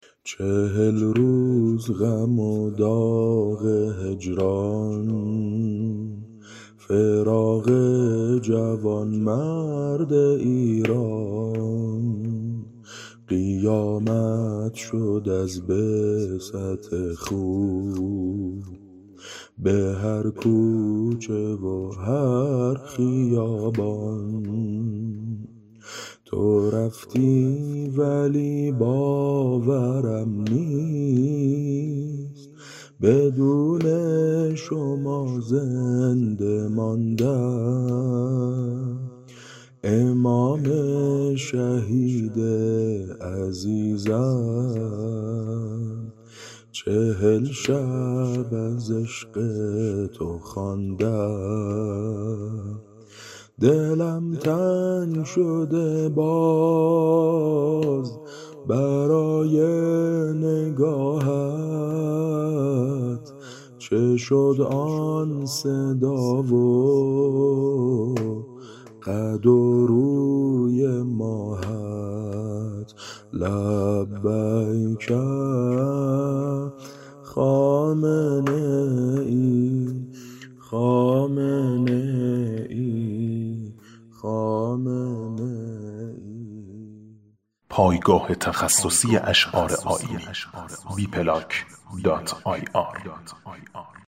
نوحه - - -
به سبک: کنار قدم‌ های جابر